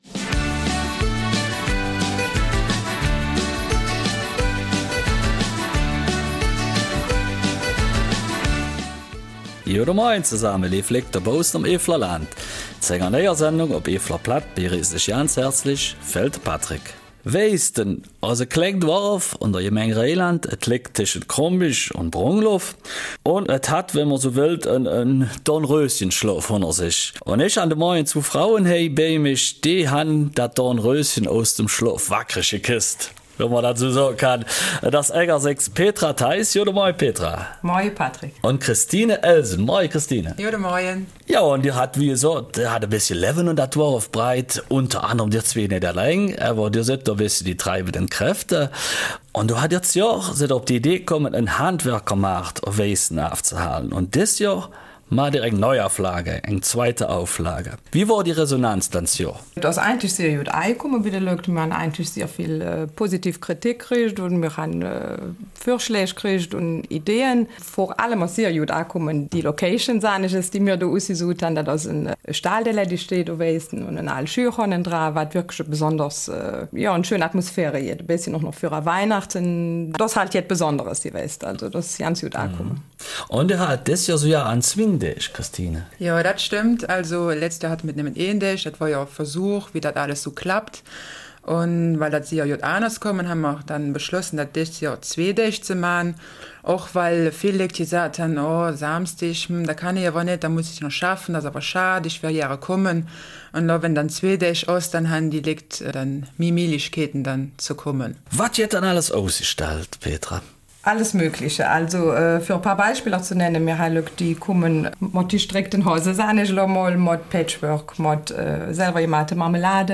Eifeler Mundart: Handwerkermarkt in Weisten